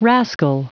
Prononciation du mot rascal en anglais (fichier audio)
Prononciation du mot : rascal